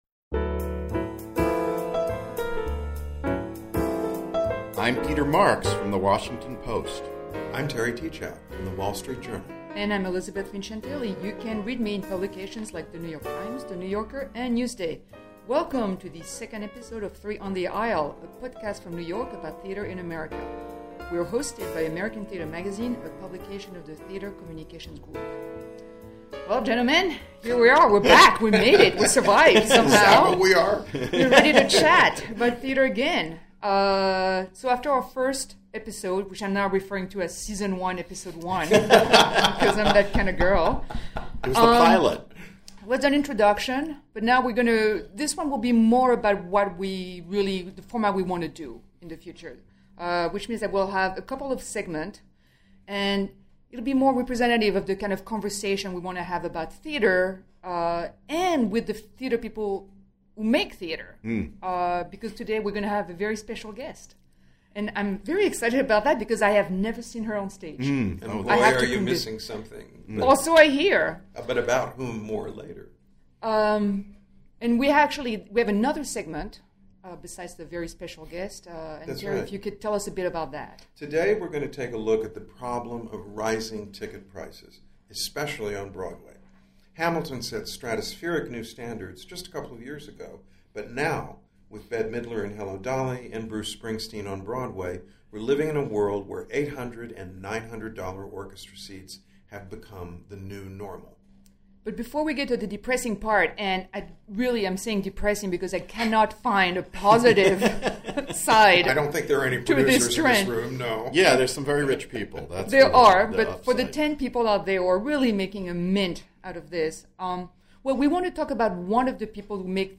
Also: a critics’ discussion about what to do about runaway ticket prices.